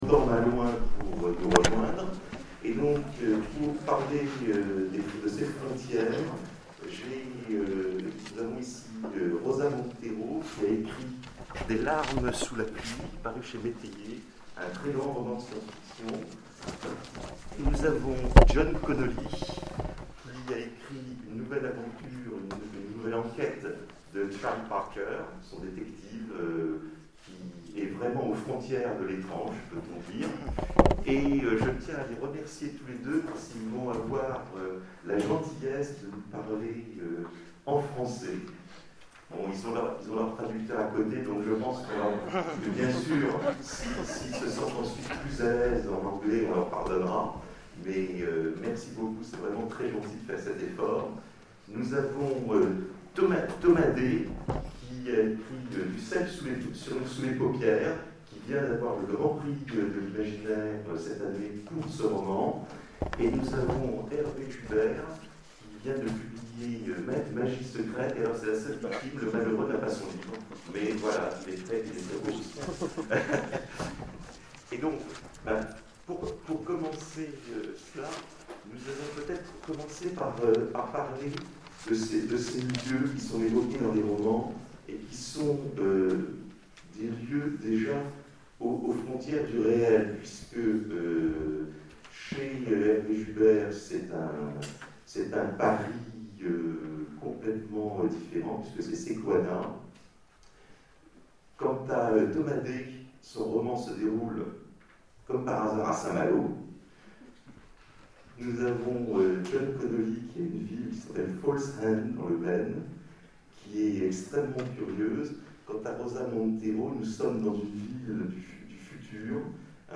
Etonnants Voyageurs 2013 : Conférence Aux frontières du réel